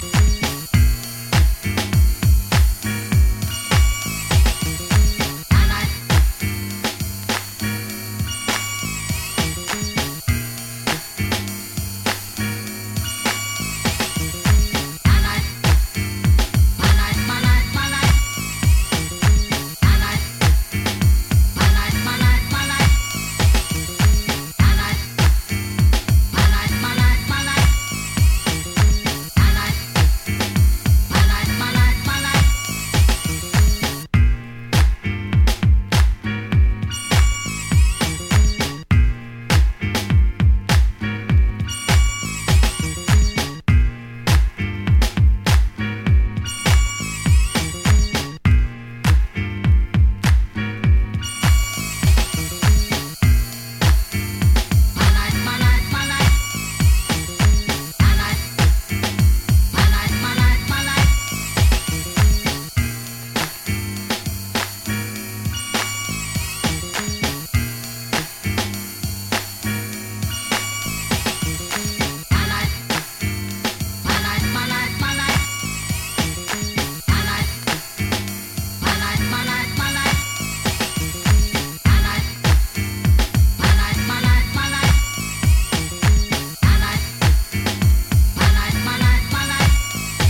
Disco Funk